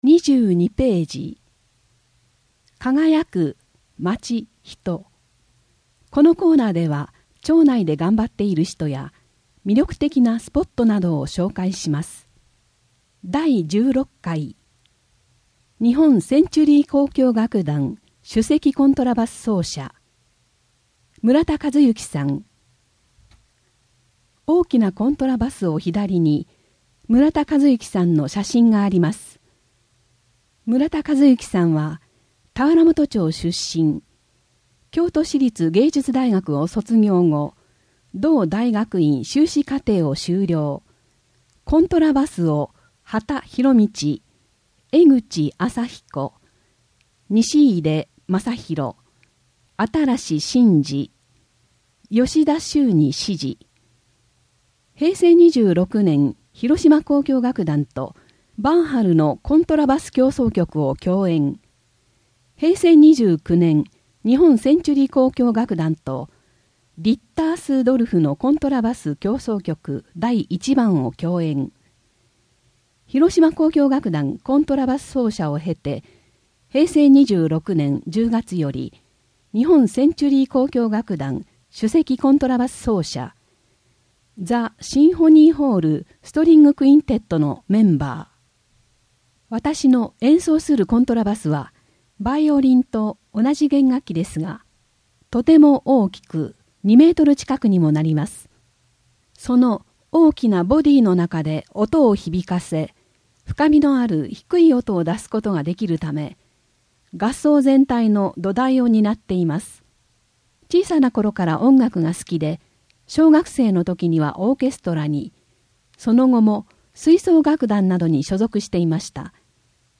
音訳広報たわらもと20～21ページ (音声ファイル: 6.0MB)